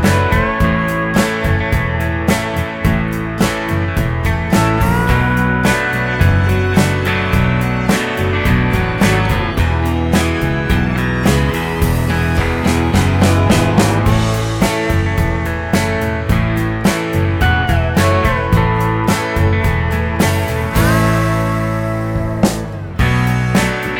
no Backing Vocals Country (Male) 4:06 Buy £1.50